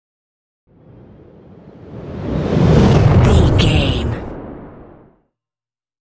Dramatic whoosh to hit trailer
Sound Effects
Atonal
dark
intense
tension
woosh to hit